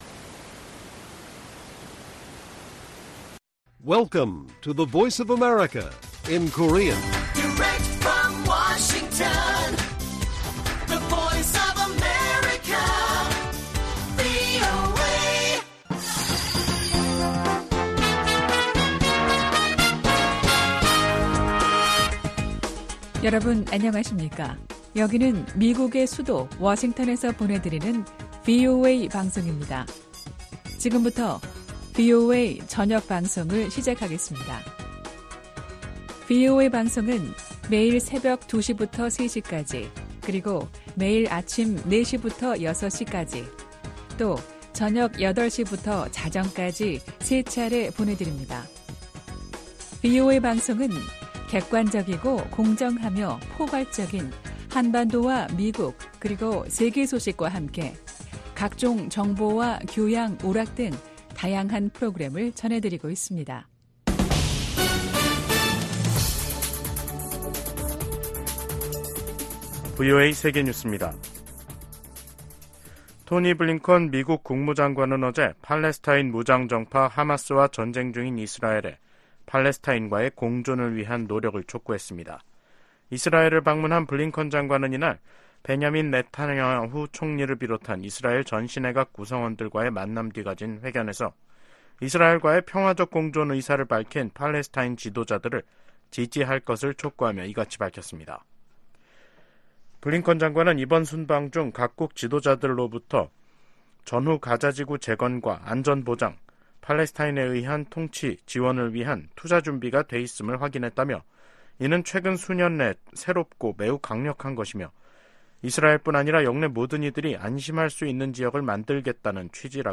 VOA 한국어 간판 뉴스 프로그램 '뉴스 투데이', 2024년 1월 10일 1부 방송입니다. 러시아가 또다시 우크라이나 공격에 북한산 탄도미사일을 사용했다고 백악관이 밝혔습니다. 미국과 한국,일본 등 49개국 외교장관이 공동성명을 내고 러시아의 북한 탄도미사일 사용을 강력 규탄했습니다. 김정은 북한 국무위원장은 한국을 주적으로 규정하면서 무력으로 자신들을 위협하면 초토화하겠다고 위협했습니다.